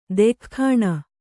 ♪ dekhkhāṇa